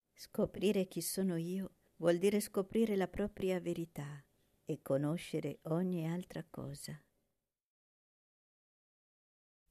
nr.9 Non sono niente canto mp3 – Preghiera mp3